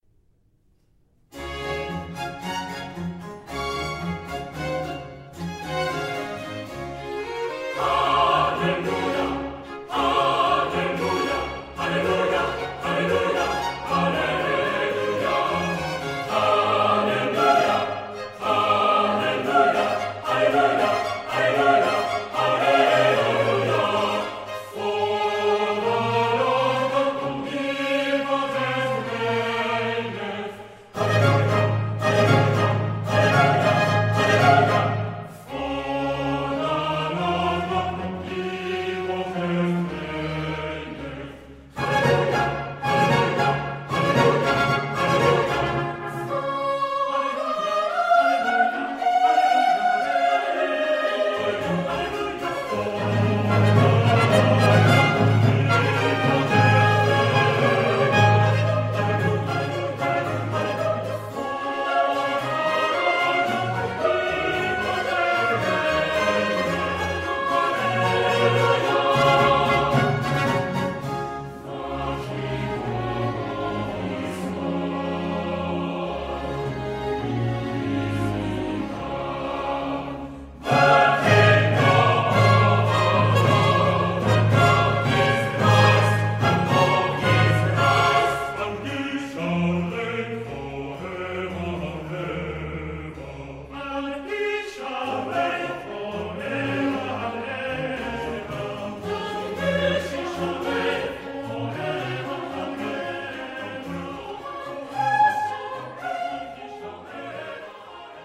Part 2 Chorus